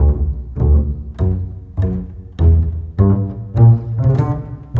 Rock-Pop 10 Bass 02.wav